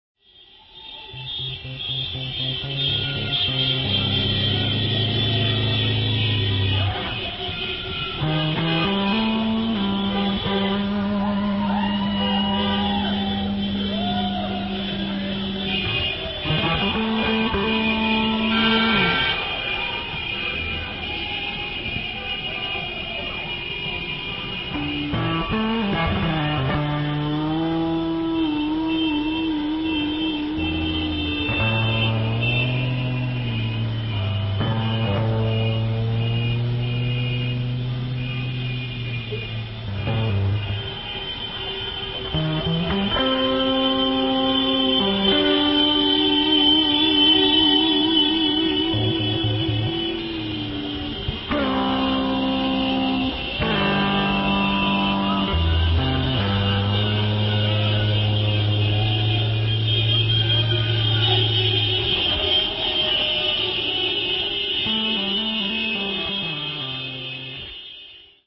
They are all first takes.